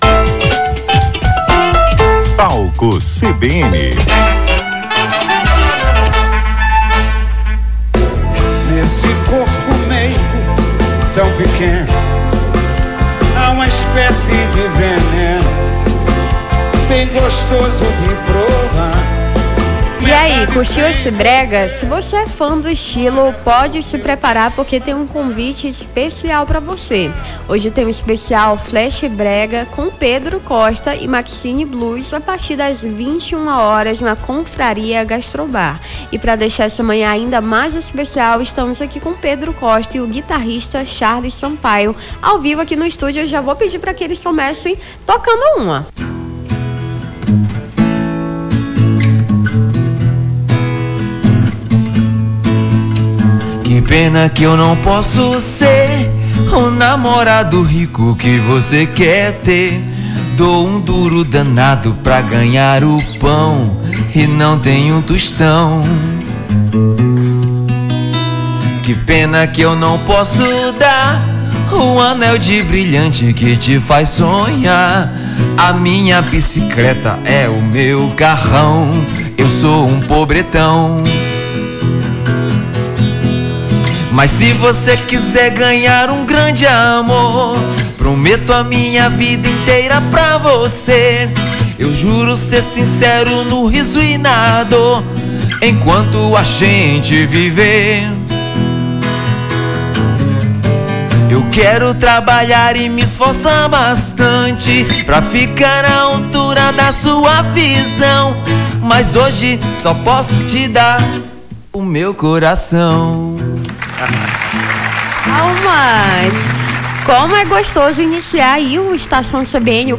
conversou com os músicos